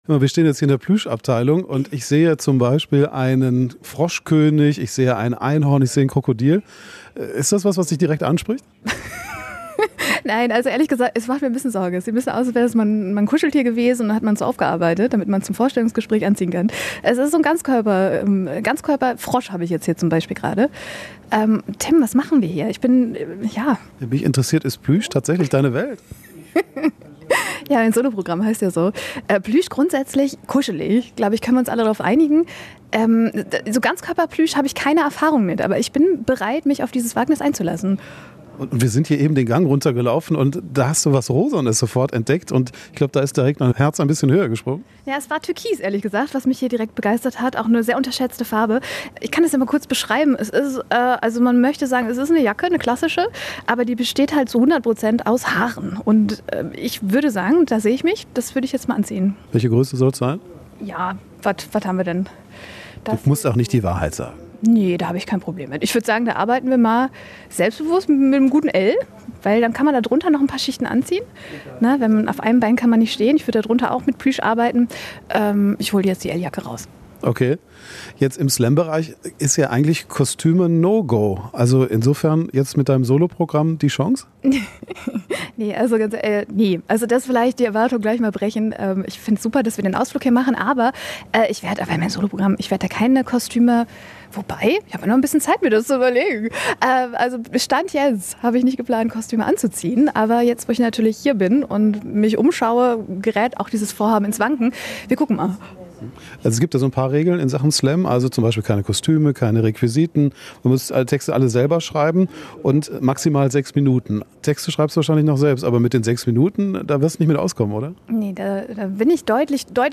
Vor der Premiere treffen wir sie zwischen ganz viel Plüsch zum Interview.